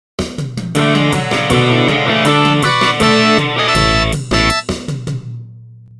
ringtone2